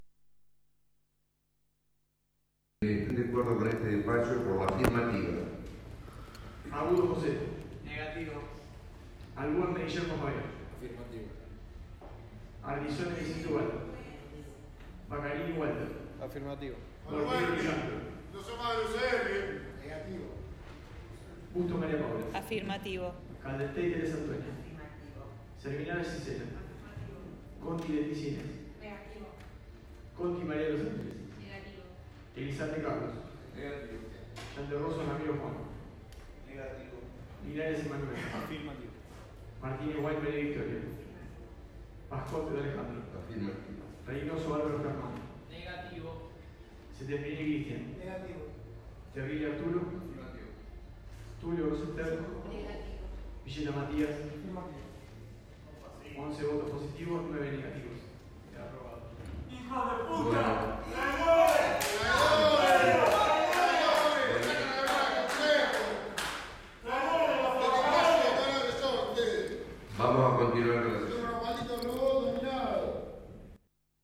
2da_sesion_hcd_pergaminoEn la tarde/noche del martes se llevó a cabo una nueva sesión ordinaria del Honorable Concejo Deliberante, la sexta, con un extenso temario fuera y dentro del orden del día.
(AUDIO) Un grupo desconocido de personas insultaron a los ediles que votaron a favor de la derogación.